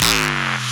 Clip Bass.wav